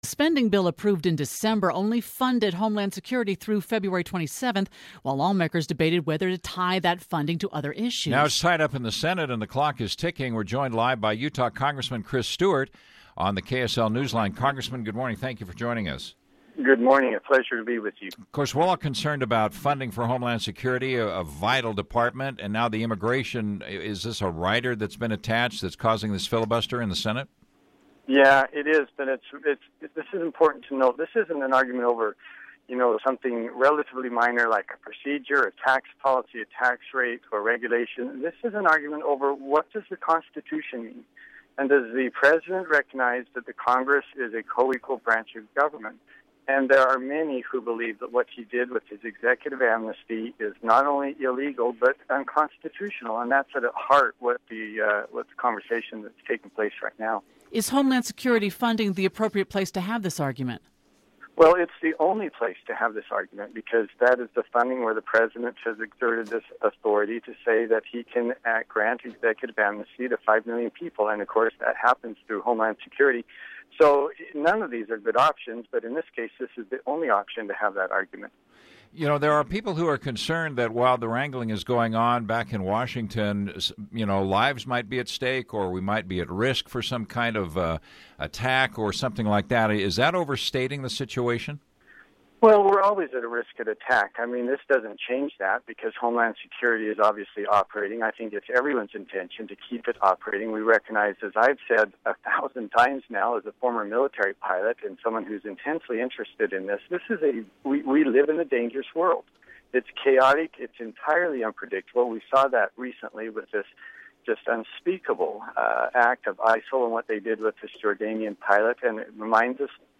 Congressman Chris Stewart talks about funding Homeland Security and the debate over Executive actions.
A spending bill approved in December only funded Homeland Security through February 27th. We asked Utah Congressman Chris Stewart what he thinks about the stalemate.